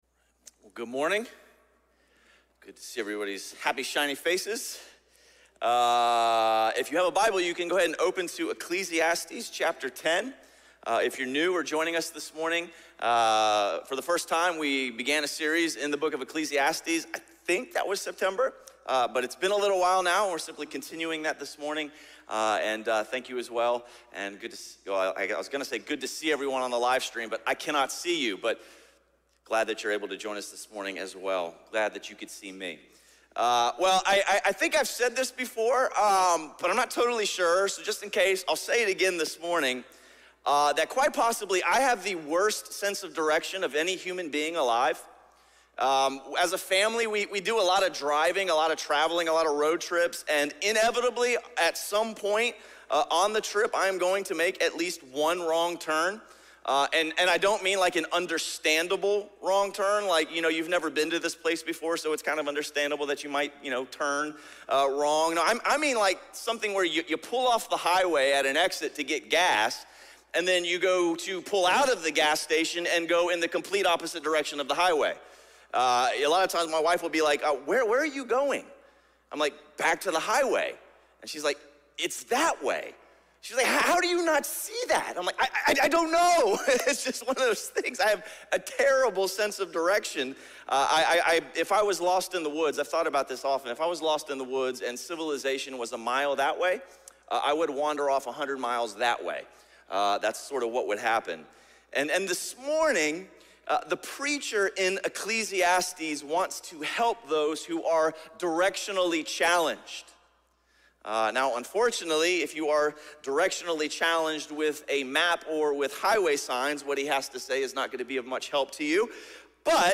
Sermon series through the book of Ecclesiastes.